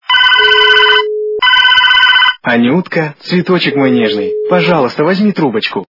» Звуки » Смешные » Голос - Анюта возьми трубочку
При прослушивании Голос - Анюта возьми трубочку качество понижено и присутствуют гудки.